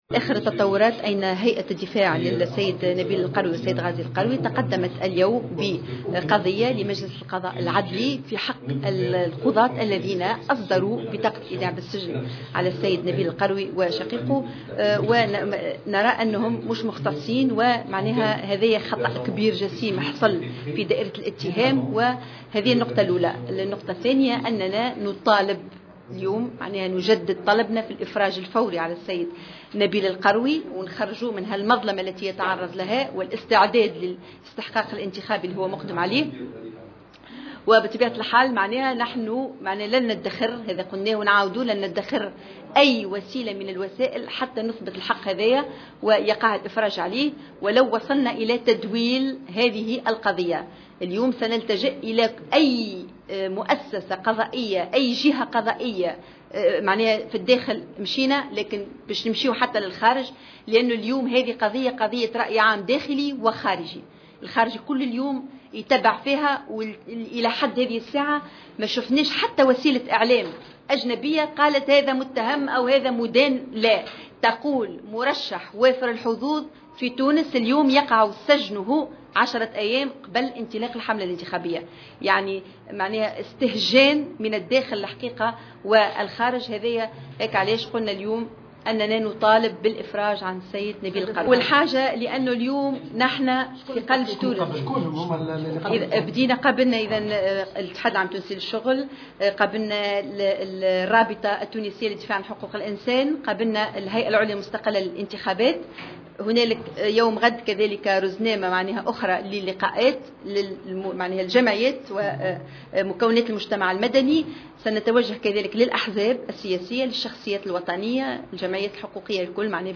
ووصفت الشواشي، في تصريح لمراسل الجوهرة أف أم، على هامش ندوة صحفية عقدتها هيئة الدفاع عن الموقوفين، اليوم الثلاثاء، قرار القضايين بالخطأ الجسيم، معلنة أن الهيئة ستعمل على تدويل القضية .